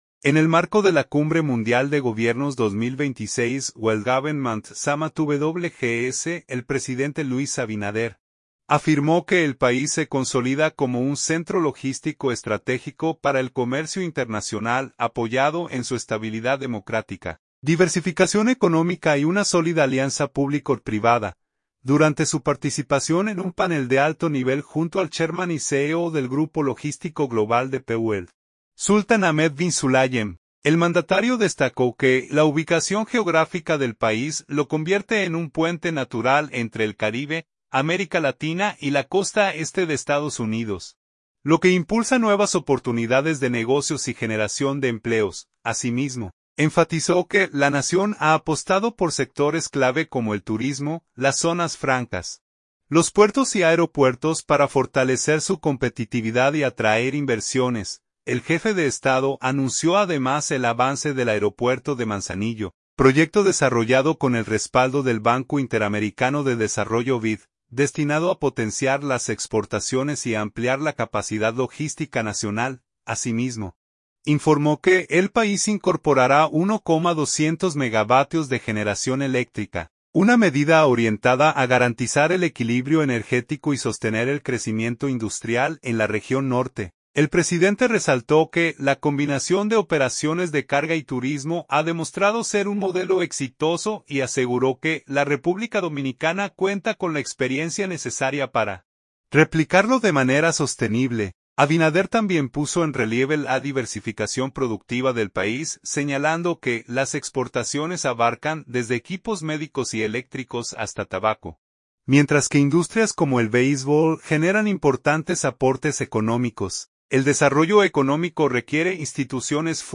Durante su participación en un panel de alto nivel junto al chairman y CEO del grupo logístico global DP World, Sultan Ahmed bin Sulayem, el mandatario destacó que la ubicación geográfica del país lo convierte en un puente natural entre el Caribe, América Latina y la costa este de Estados Unidos, lo que impulsa nuevas oportunidades de negocios y generación de empleos.
“El desarrollo económico requiere instituciones fuertes, respeto a la ley y políticas que fomenten la confianza de los inversionistas”, proyectó el mandatario ante una audiencia integrada por jefes de Estado, inversionistas y tomadores de decisiones globales.